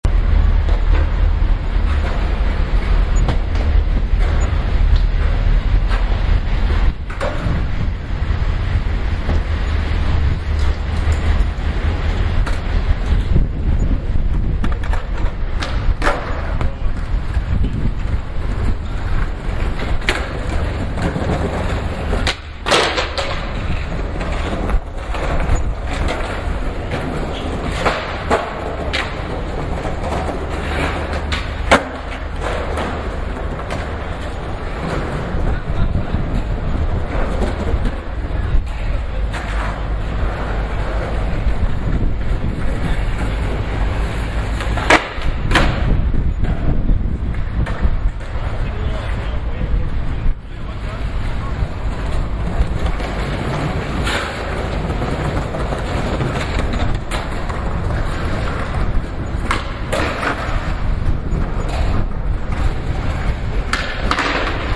伦敦市中心的双耳录音 " 滑铁卢，南岸滑冰者
Tag: 双耳 环境 存储 伦敦 部门 氛围 现场录音